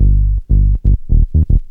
01SYN.LICK.wav